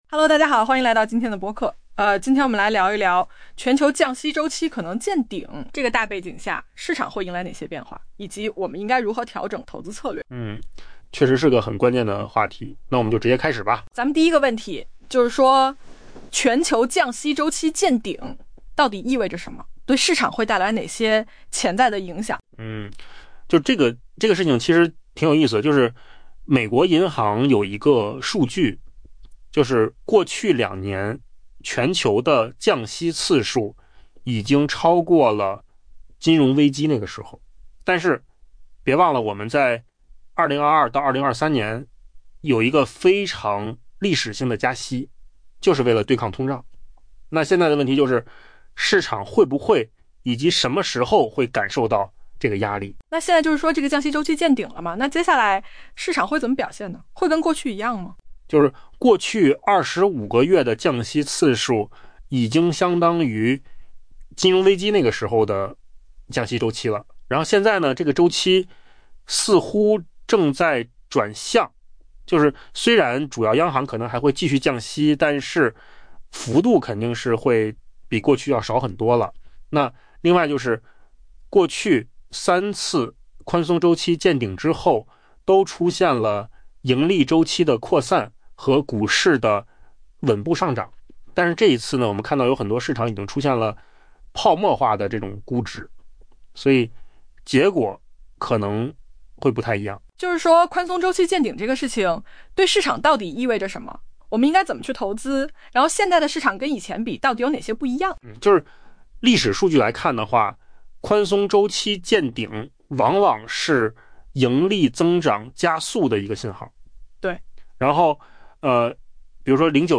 AI 播客：换个方式听新闻 下载 mp3 音频由扣子空间生成 全球降息周期可能已见顶。